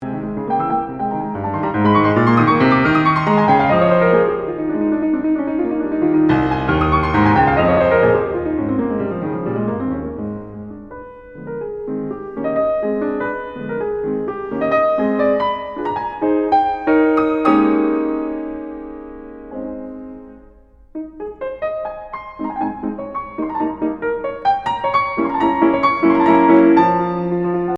and here is the end of the exposition, it ends at 0.20. At that point, the development starts with the theme from the beginning, played in the “new” tonality: